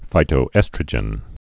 (fītō-ĕstrə-jən)